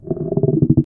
massive stone pillar rotating
massive-stone-pillar-rota-3weqqmrc.wav